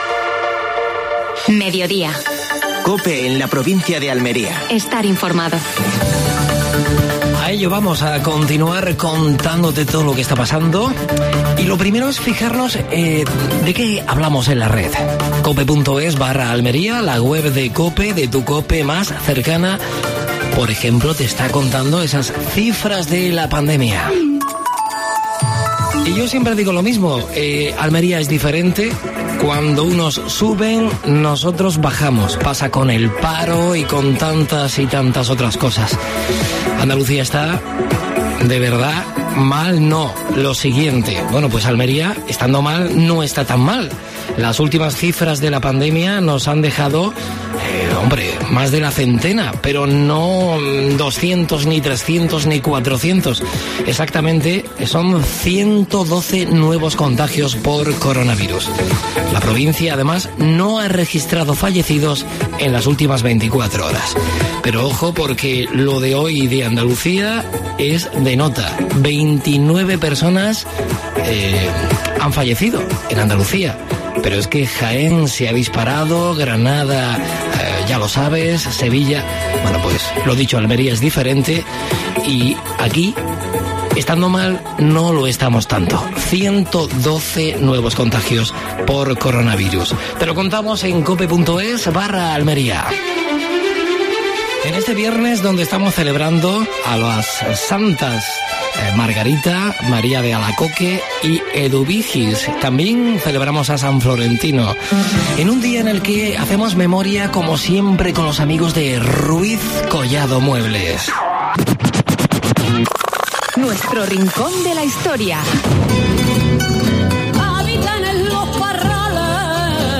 AUDIO: Actualidad en Almería. Entrevistas a Javier Aureliano García